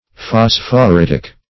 Search Result for " phosphoritic" : The Collaborative International Dictionary of English v.0.48: Phosphoritic \Phos`phor*it"ic\, a. (Min.) Pertaining to phosphorite; resembling, or of the nature of, phosphorite.
phosphoritic.mp3